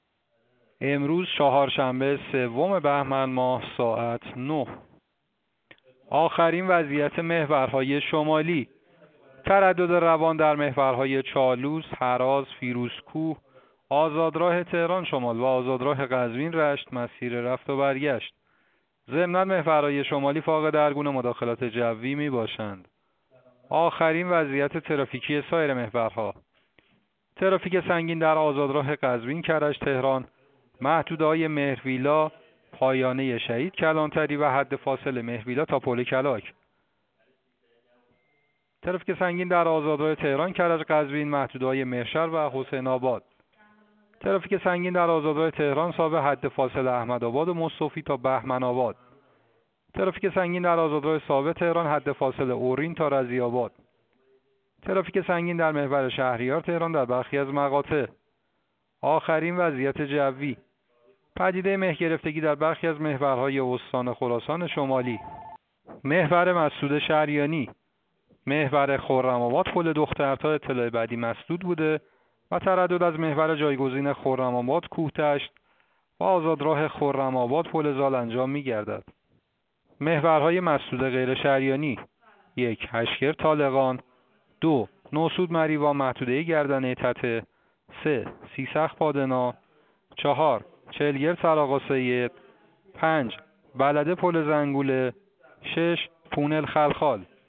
گزارش رادیو اینترنتی از آخرین وضعیت ترافیکی جاده‌ها ساعت ۹ سوم بهمن؛